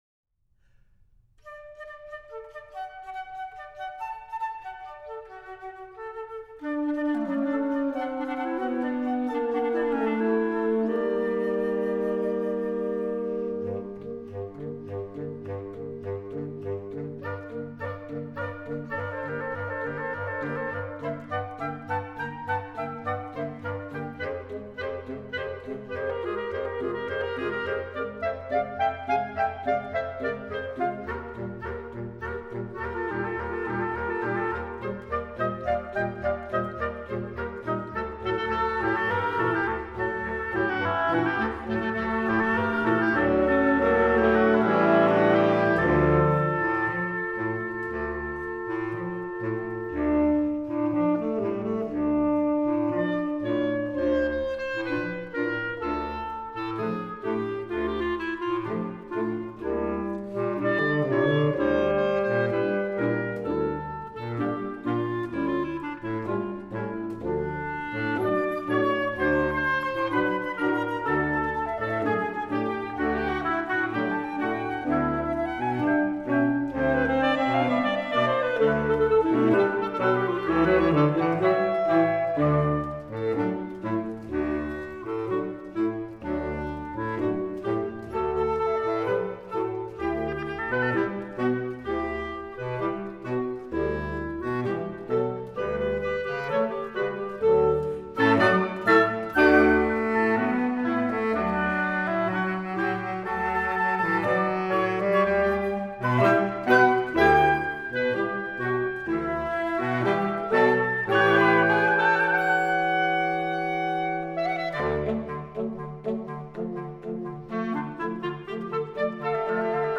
Partitions pour octuor flexible.